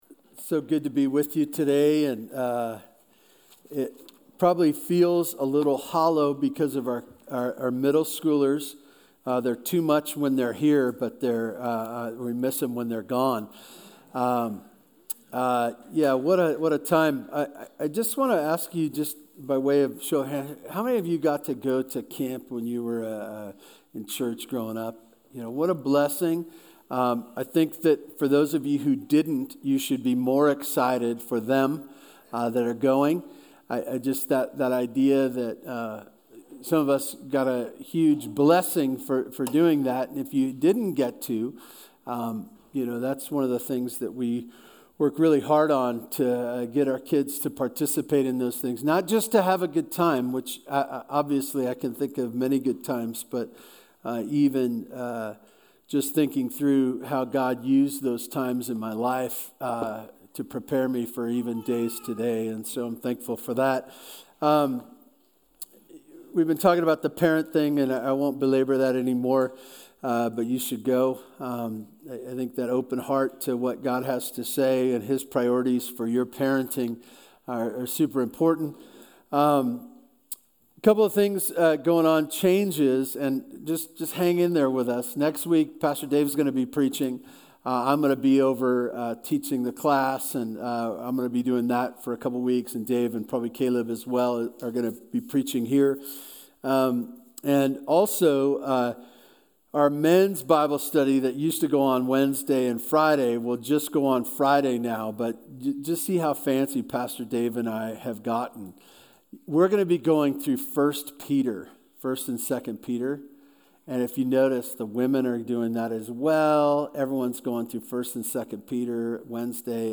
BVC Sunday Sermons
Sermons from Bear Valley Church: Tehachapi, CA